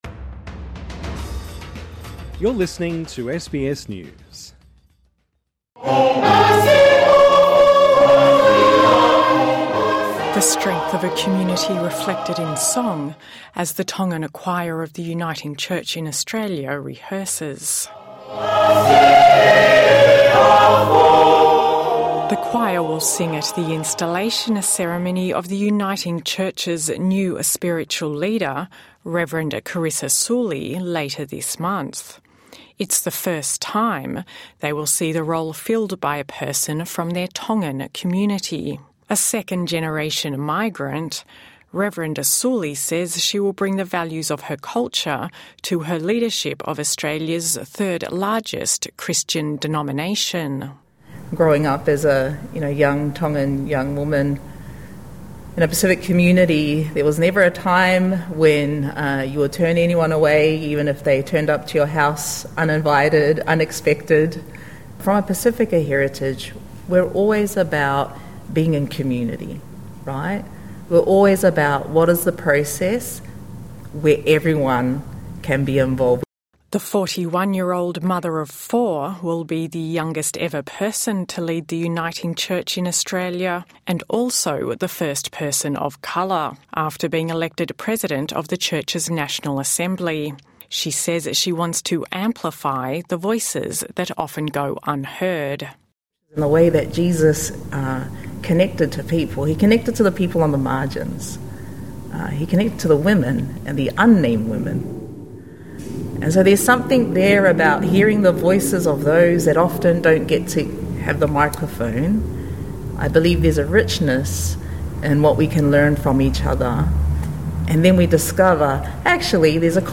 TRANSCRIPT (Choir singing) The strength of a community reflected in song - as the Tongan Choir of the Uniting Church in Australia rehearses.